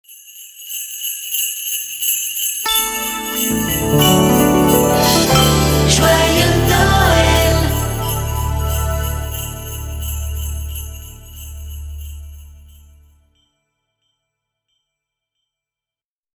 Père noël